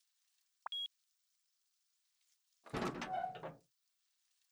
Sheet Metal Door Unlocks Then Opens